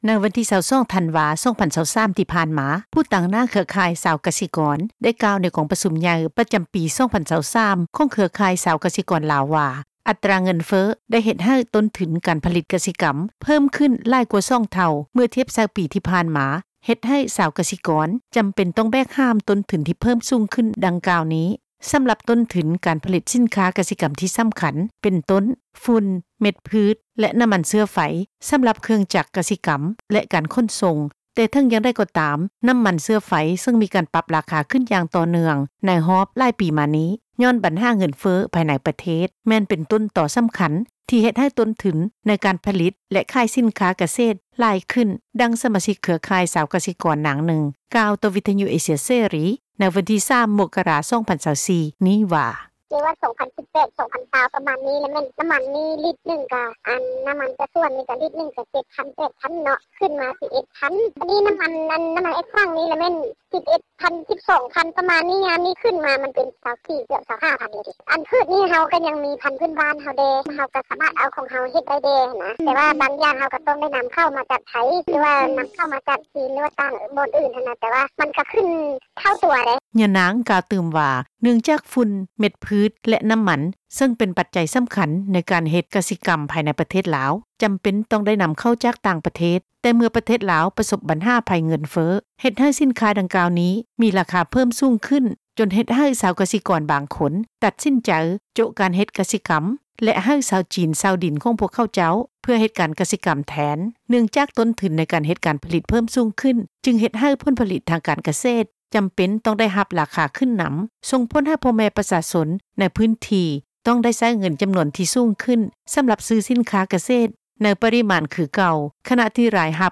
ດັ່ງ ສະມາຊິກເຄືອຂ່າຍ ຊາວກະສິກອນ ນາງນຶ່ງ ກ່າວຕໍ່ວິທຍຸ ເອເຊັຽເສຣີ ໃນມື້ວັນທີ 3 ມົກຣາ 2024 ນີ້ວ່າ: